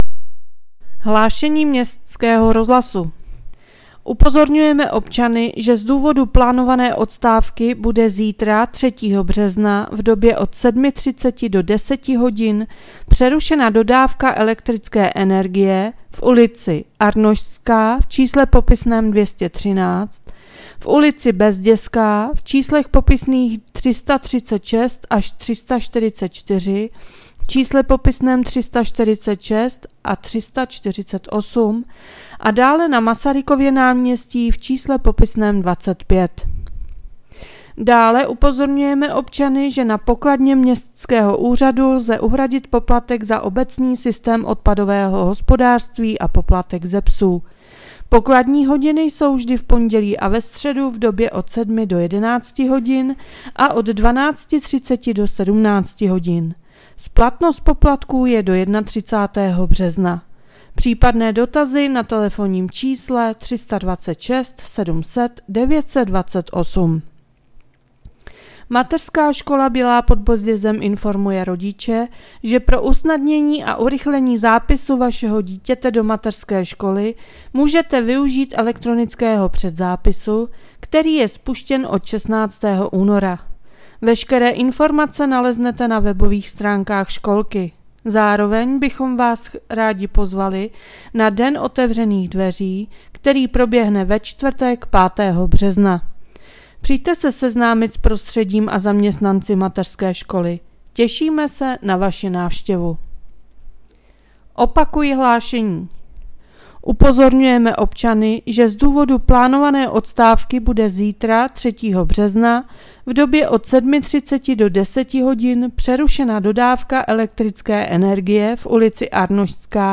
Hlášení městského rozhlasu 2.3.2026